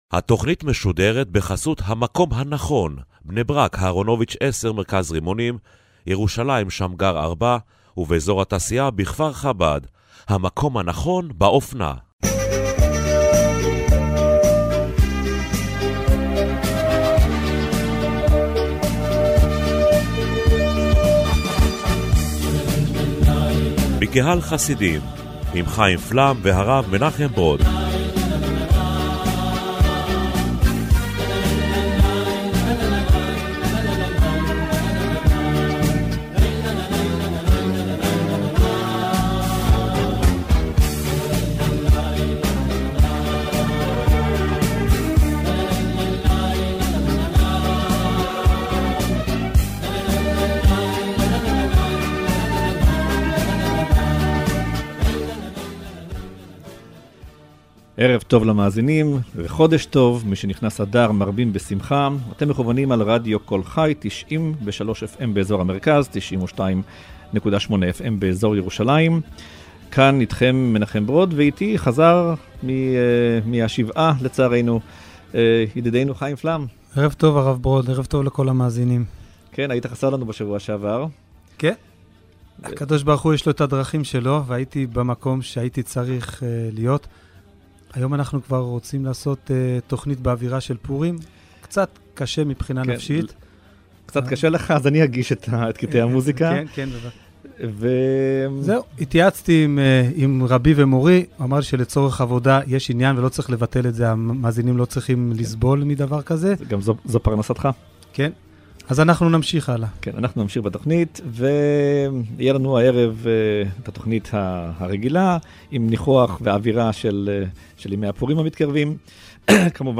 החסידות לימדה שגם בעל עסק ובעל מלאכה עושה משכן לקבה בתוך עבודתו - זה היה הנושא המרכזי בתכנית הרדיו השבועית בקהל חסידים, ששודרה אמש.